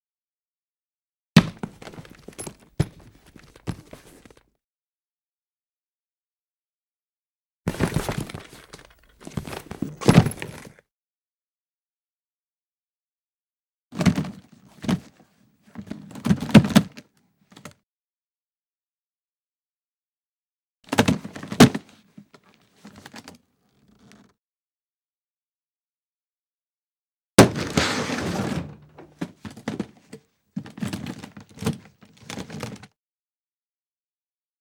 household
Case Luggage Noise